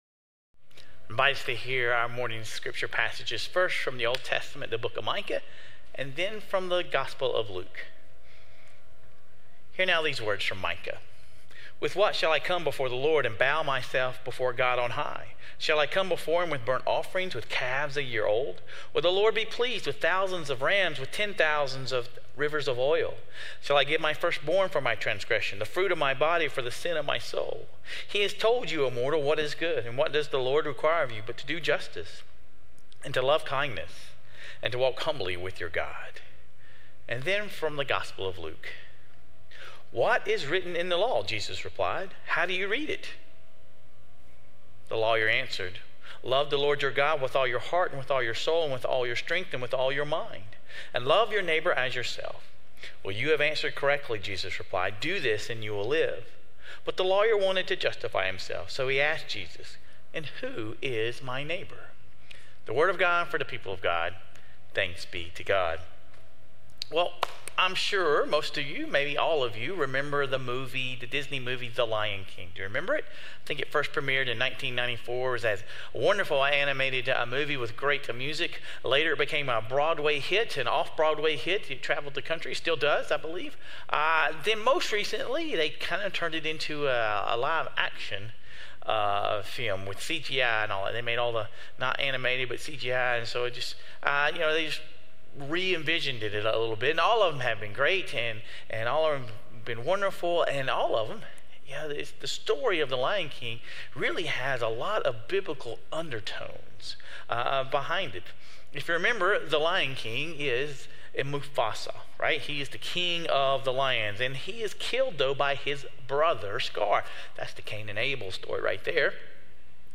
This week, we wrap our series putting Micah’s call into greater context, using Christ’s example of loving God and loving our neighbor. Sermon Reflections: Reflect on a recent situation where you had to make a judgment about someone.